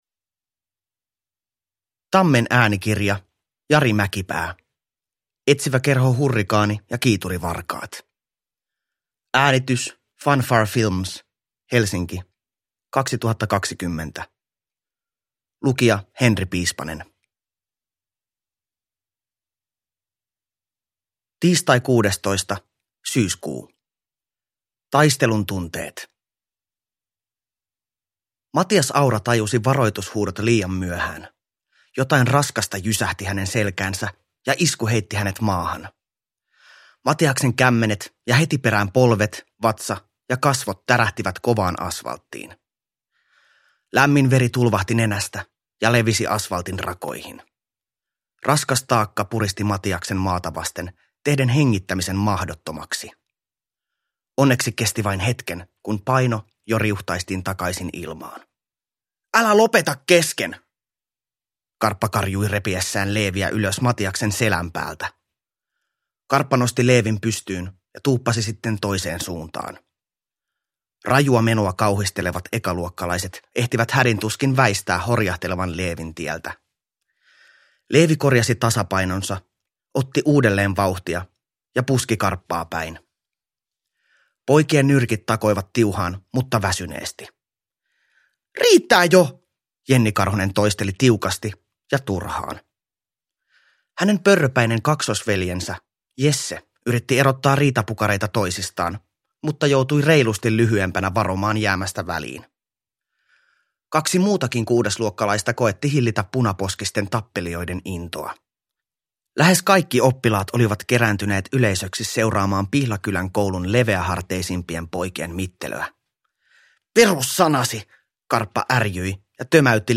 Etsiväkerho Hurrikaani ja kiiturivarkaat – Ljudbok – Laddas ner